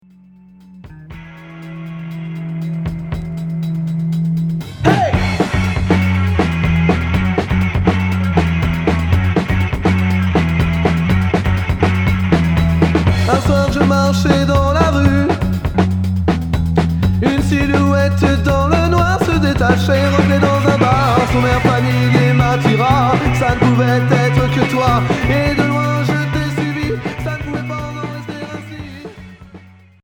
Rock punk Unique 45t retour à l'accueil